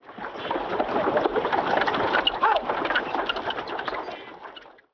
Ambient
wagon7.wav